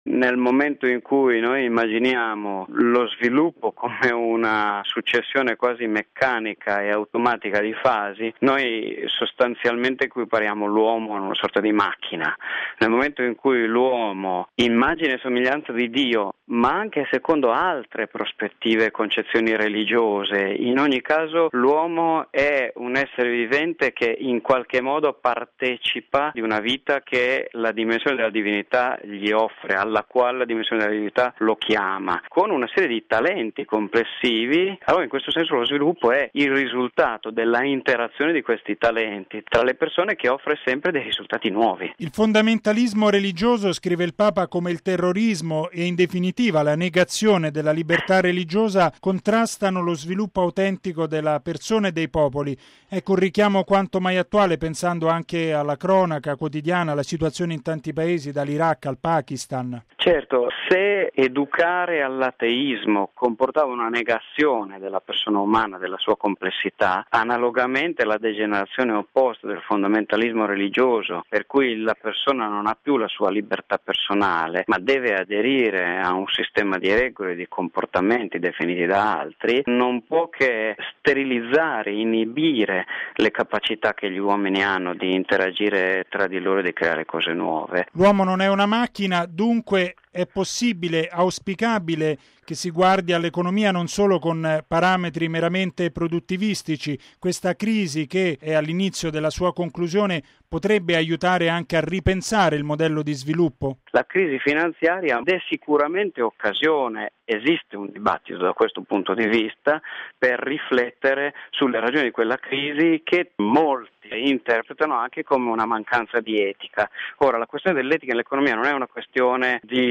ha intervistato l’economista